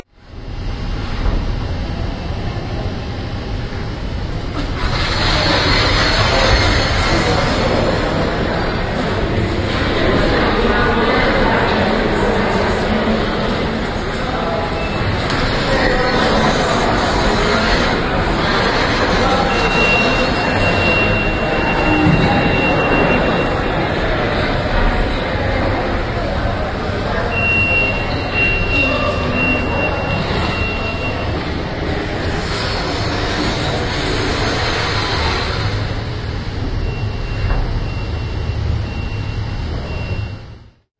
滑动浴室玻璃门
描述：将玻璃门拖到金属导轨上产生滑动的声音。
Tag: LCS13 滑动 浴室 家具